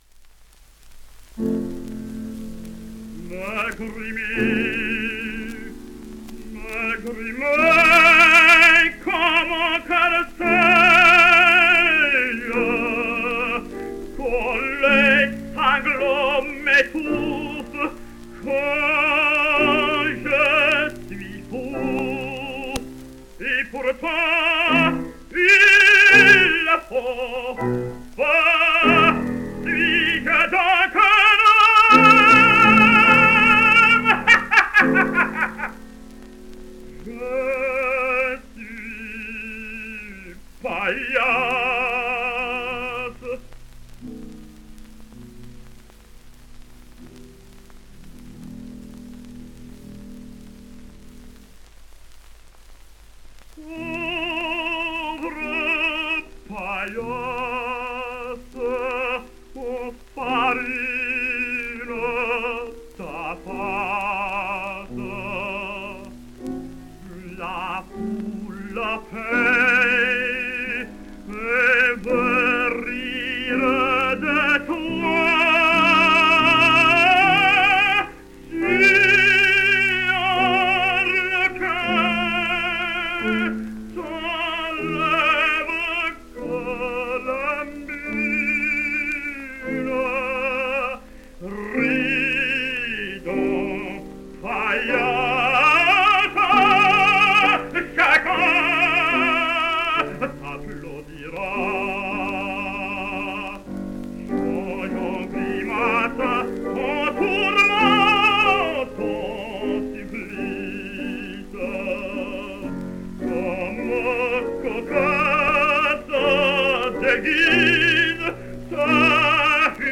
Now a tenor of that name has never had any solo career, and a chorister or amateur can be excluded if you hear the recordings: this is a full-fledged dramatic tenor of impressive stature.